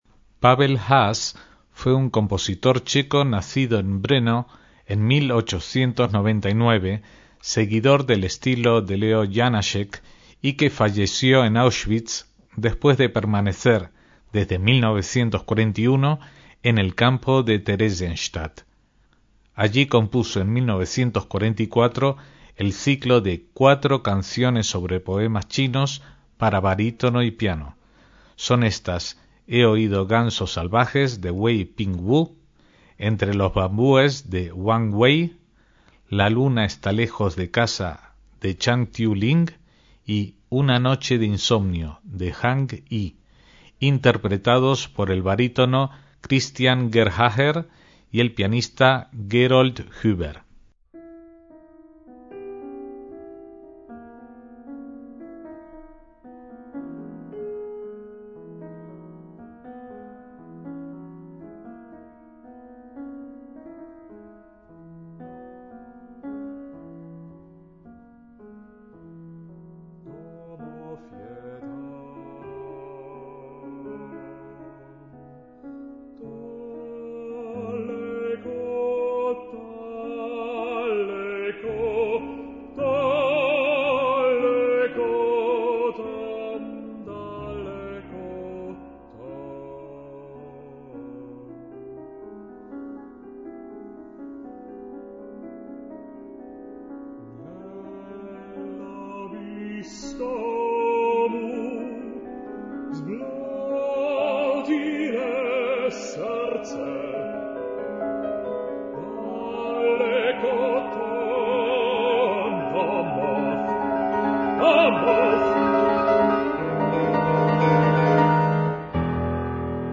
MÚSICA CLÁSICA
barítono
piano